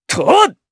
Lucias-Vox_Attack3_jp.wav